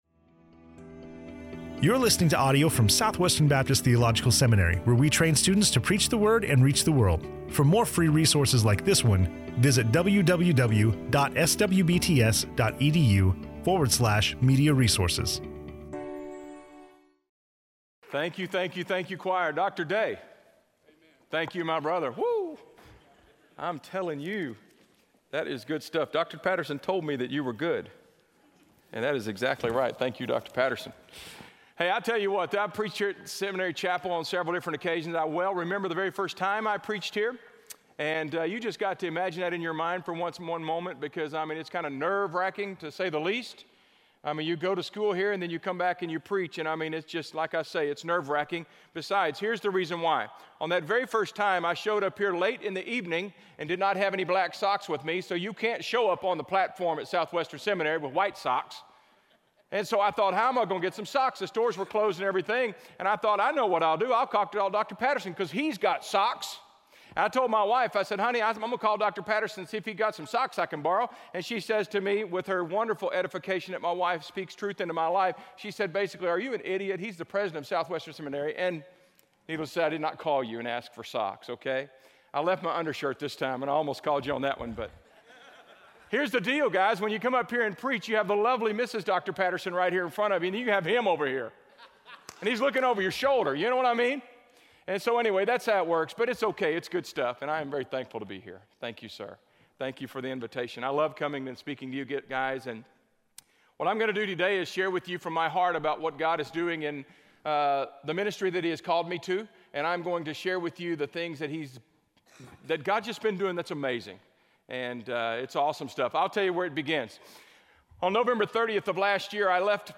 SWBTS Chapel Sermons